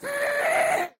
MinecraftConsoles / Minecraft.Client / Windows64Media / Sound / Minecraft / mob / ghast / scream1.ogg
scream1.ogg